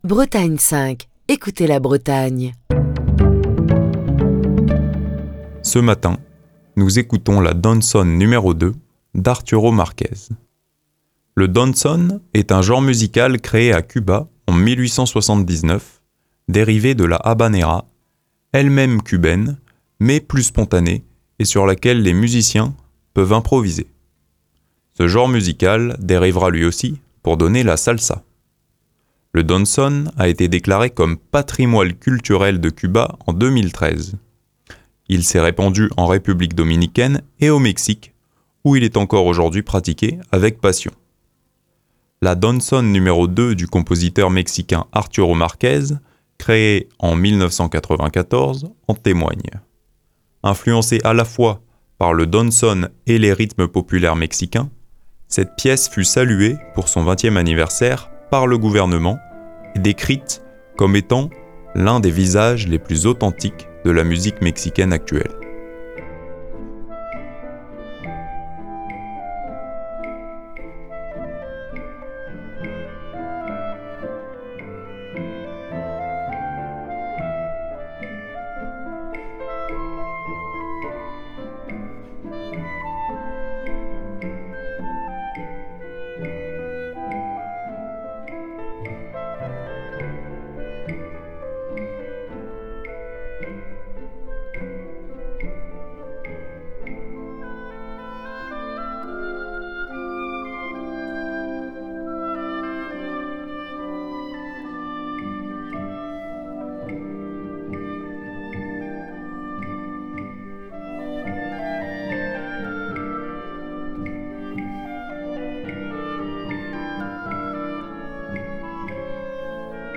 dans une version live pleine de swing et de lyrisme